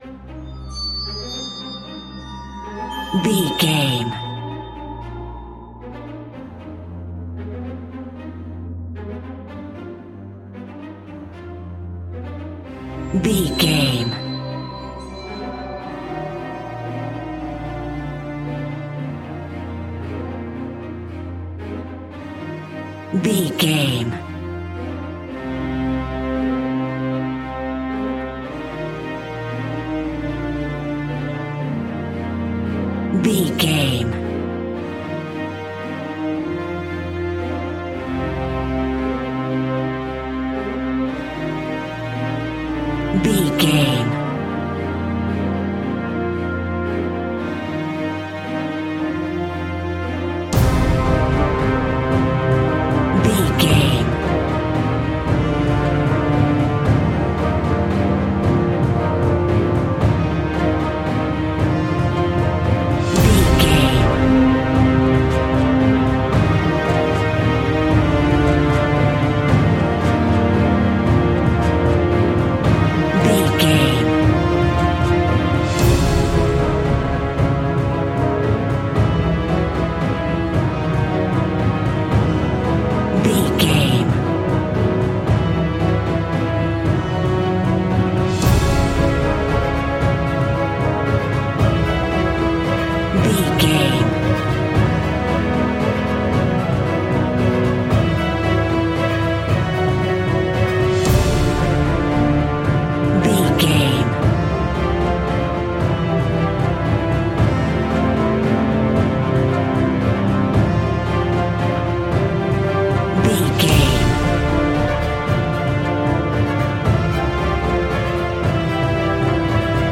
In-crescendo
Aeolian/Minor
scary
ominous
dramatic
haunting
driving
heavy
intense
orchestra
strings
brass
percussion
violin
cello
double bass
cinematic
cymbals
gongs
viola
french horn trumpet
taiko drums
timpani